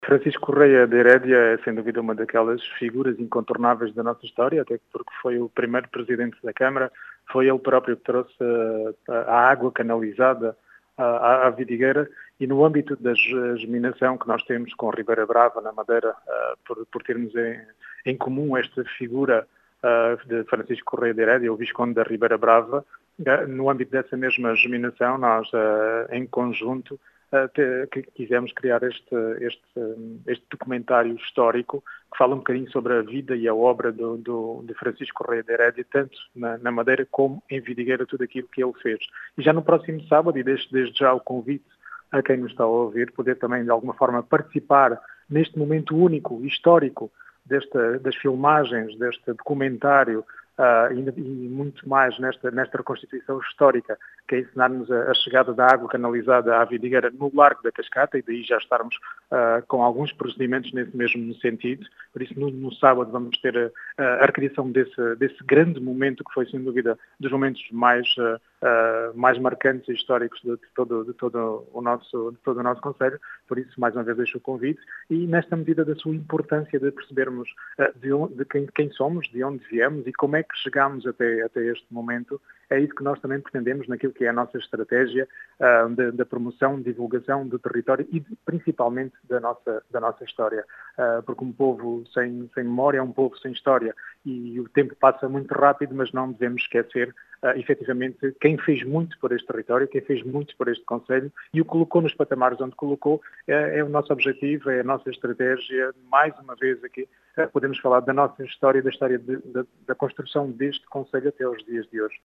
As explicações são de Rui Raposo, presidente da Câmara Municipal de Vidigueira, que deixa o convite para a participação neste “momento único e histórico”, que recria a história da Vidigueira.